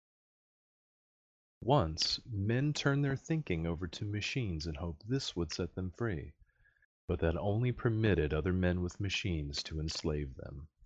For the curious, I took the liberty of recording microphone input from each headset. For comparison, I am reading an excerpt from the amazing science fiction work "Dune" by Frank Herbert.